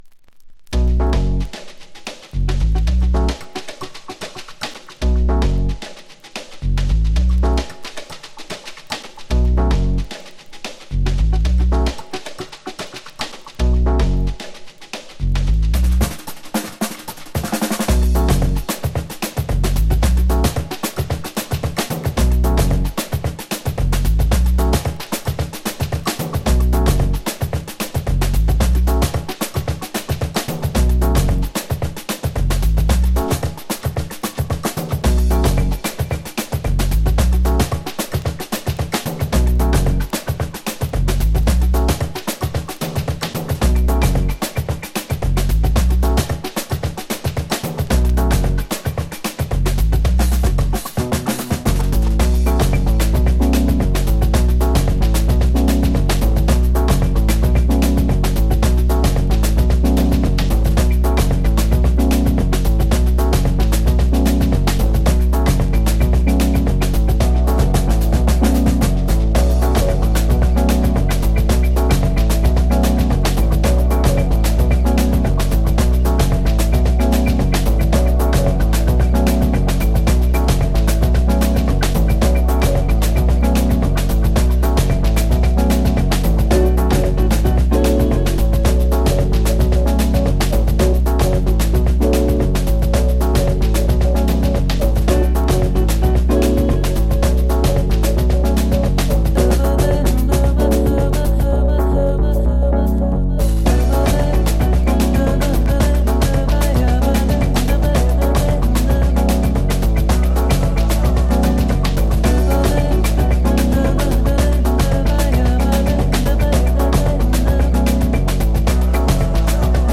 Latin Future Jazzここにあり。